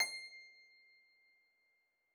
53l-pno22-C5.wav